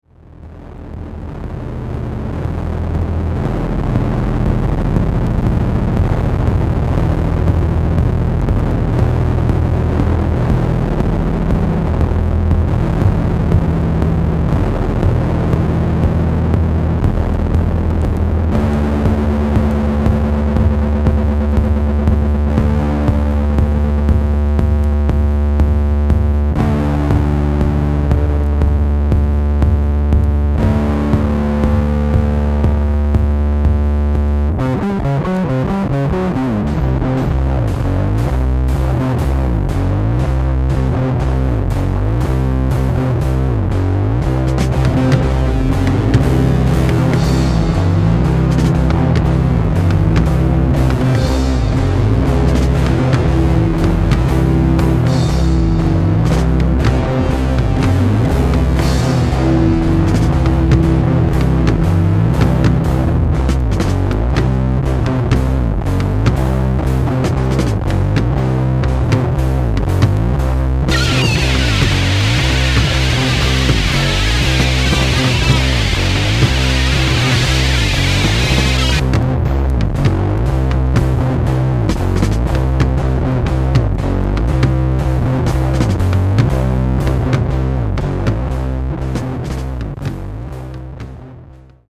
執拗に打ち返す打楽器の強迫的ビートとひずみ果てた轟音の危うい不調和が醸し出す、退廃的イメージの木霊に覆われた全7曲。
キーワード：サイケ　ミニマル　脱線パンク　即興